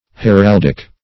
Heraldic \He*ral"dic\, a. [Cf. F. h['e]raldique.]